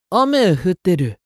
青年ボイス～シチュエーションボイス～